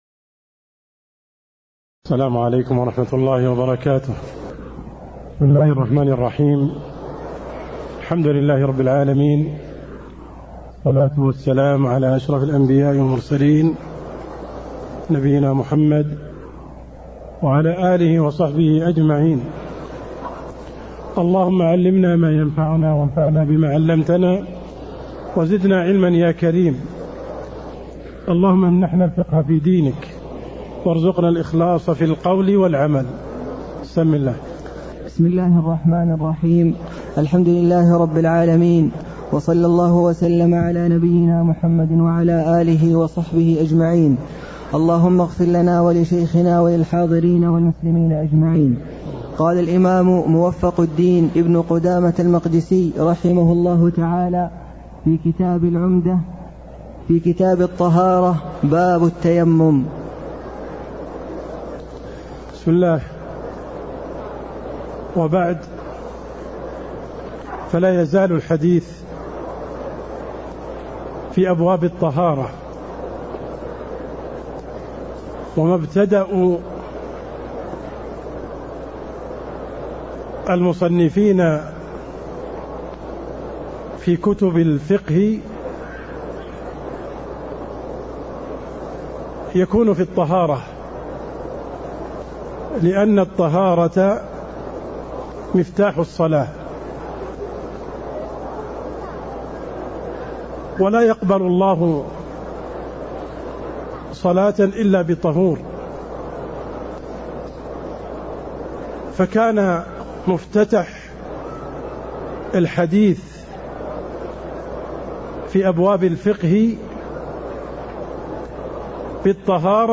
تاريخ النشر ٥ شعبان ١٤٣٥ هـ المكان: المسجد النبوي الشيخ: عبدالرحمن السند عبدالرحمن السند باب التيمّم (11)